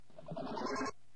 东西 " 皂液器
描述：我一次又一次地按下皂液器的头。它发出了弹簧挤压的声音。用Zoom H2.
标签： 公共域 压机 热压 机构 弹簧 挤气 压扁
声道立体声